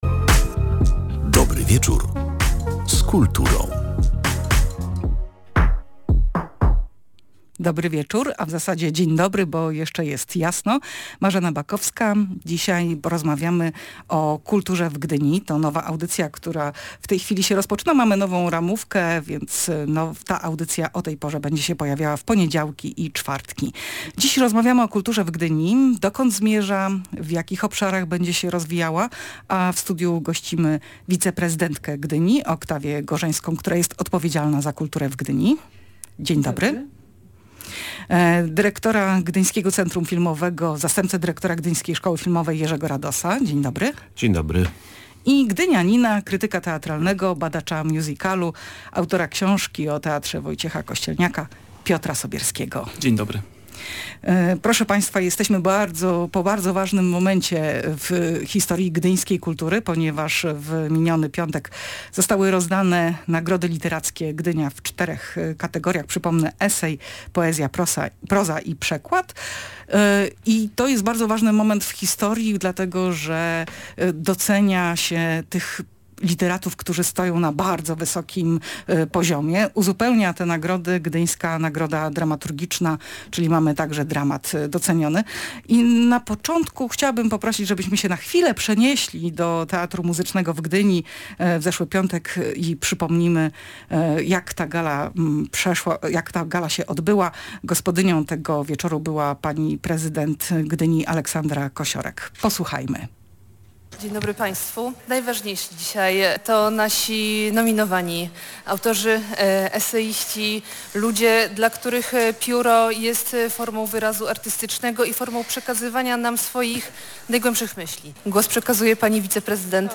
W studiu Radia Gdańsk odbyła się jedyna w swoim rodzaju debata w przestrzeni publicznej od czasu wyboru nowych władz Gdyni.